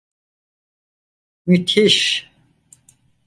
Ler máis (Inglés) terrifying terrific Frecuencia B1 Pronúnciase como (IPA) /myˈtiʃ/ Etimoloxía (Inglés) Herdado de Ottoman Turkish مدهش In summary Inherited from Ottoman Turkish مدهش (müdhiş), from Arabic مُدْهِش (mudhiš).